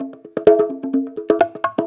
Conga Loop 128 BPM (24).wav